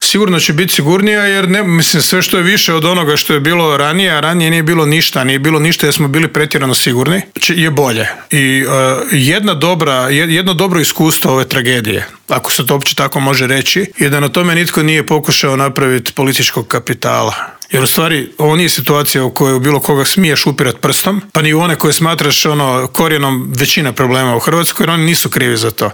ZAGREB - Od sutra, početkom drugog nastavnog polugodišta, na snagu stupa novi protokol u školama, a na njega se u specijalnom Intervjuu tjedna Media servisa osvrnuo predsjednik Republike Zoran Milanović.